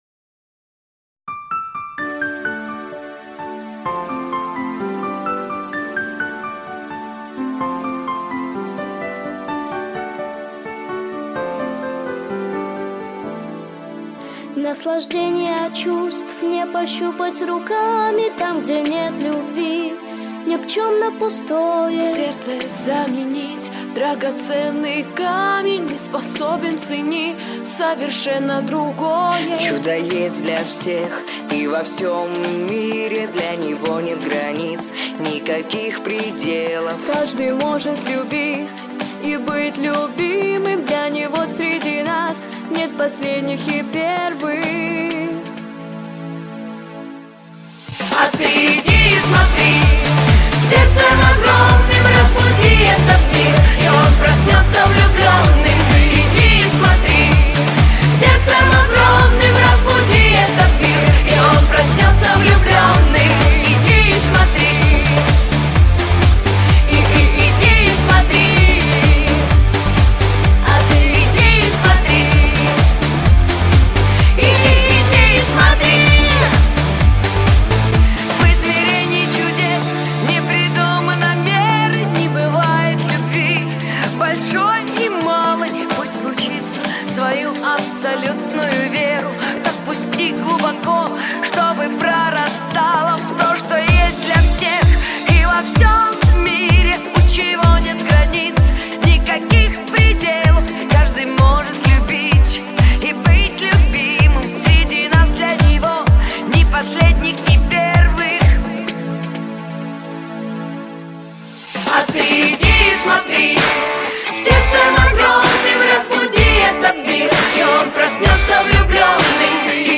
• Качество: Хорошее
Детские песни